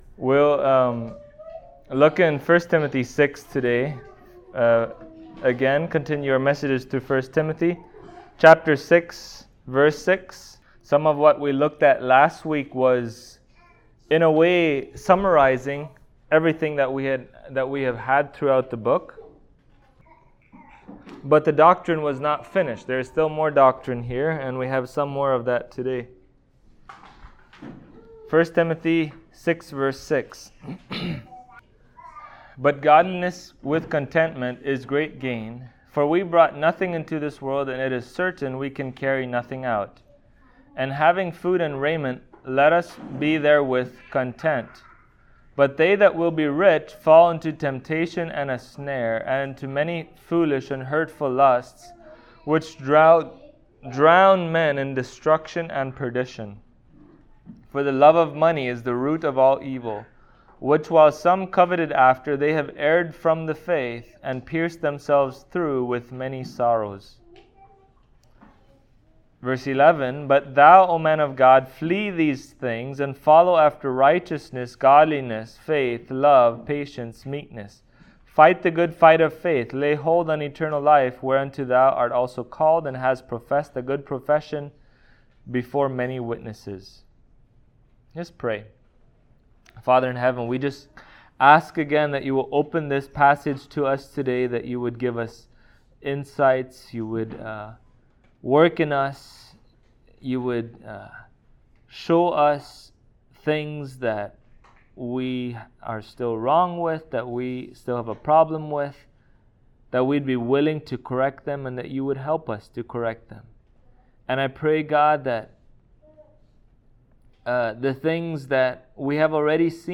1Tim 6:6-10 Service Type: Sunday Morning Christians are called to be content with the lot that the Lord has given them.